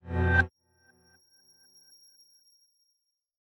meleeattack-impacts-magicaleffects-psychic-03.ogg